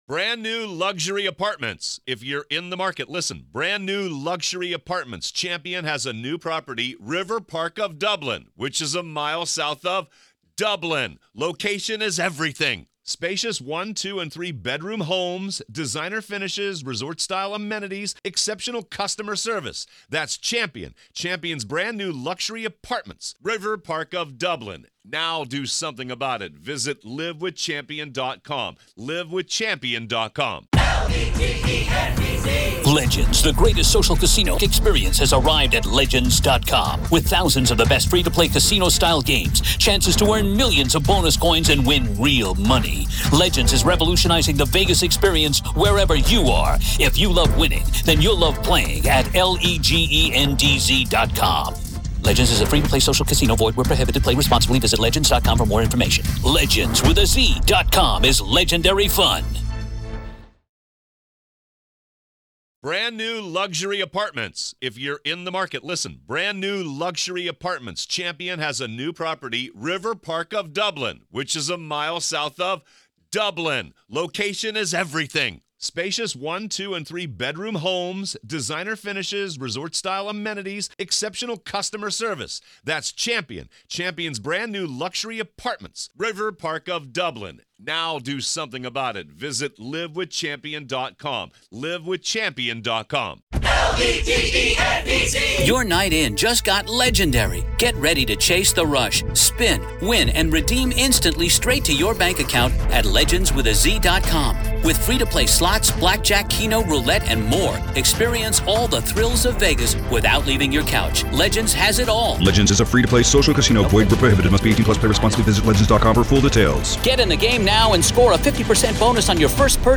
Is this an elaborate ploy to get the trial moved, a desperate attempt to sow seeds of doubt, or merely a misstep in a high-stakes game of legal chess? The conversation explores the possible repercussions of this approach, including the ethical implications and potential backlash from the judicial system.